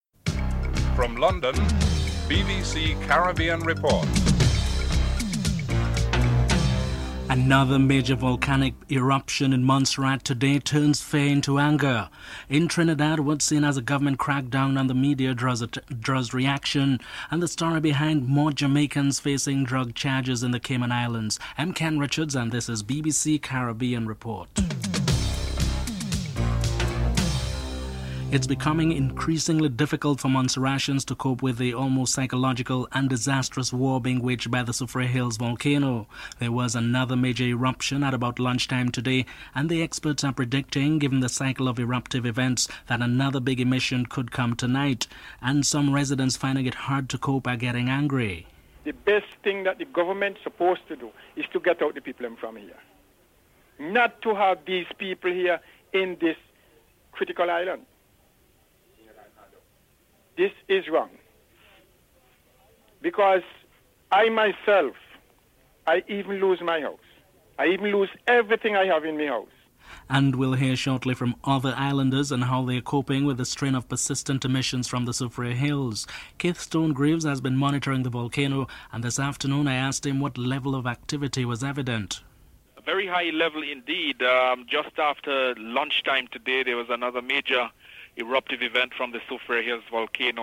1. Headlines (00:00-00:27)
4. Prime Minister Basdeo Panday is booed when he addressed the crowd during the Emancipation Day celebrations (09:48-10:13)